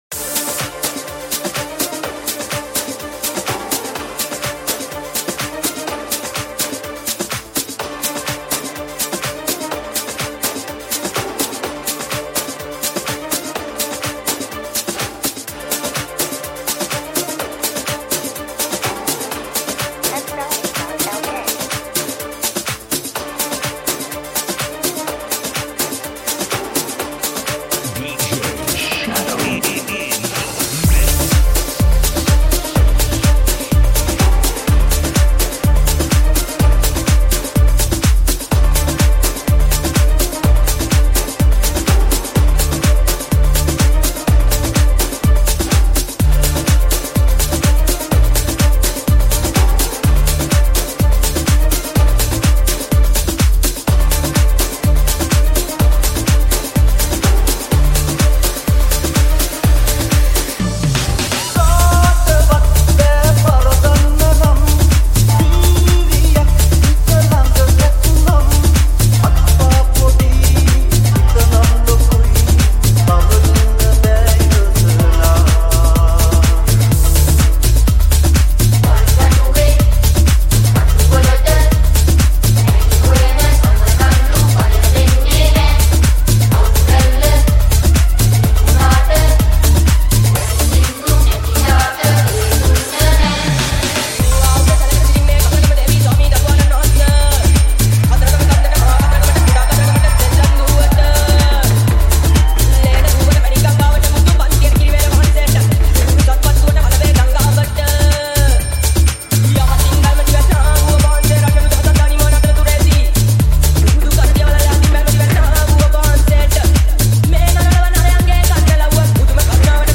Tech House Remix